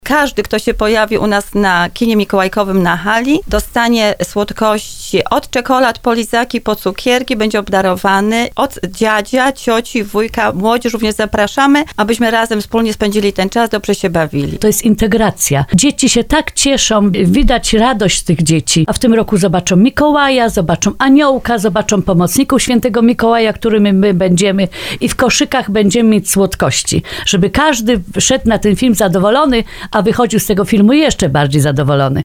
O wydarzeniu mówiły na antenie Radia RDN Małopolska